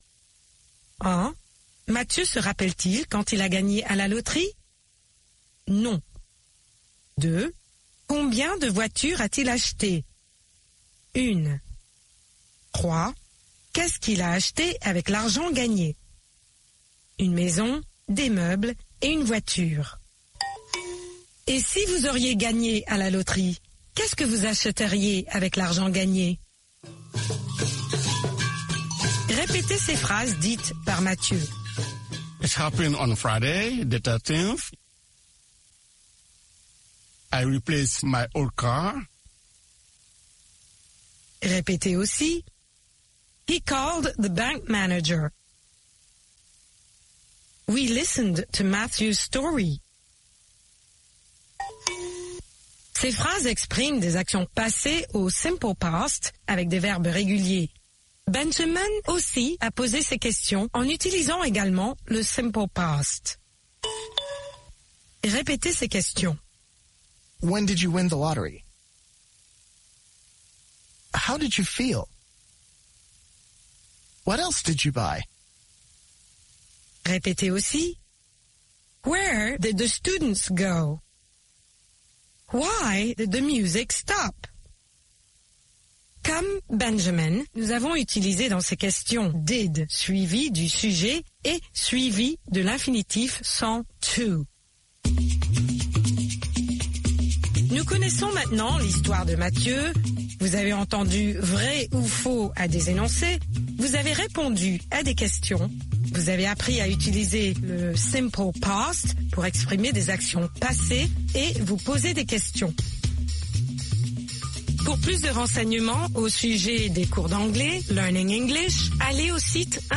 Des conversations en anglais américain par des Africains, qui ont trait à la vie quotidienne au Sénégal.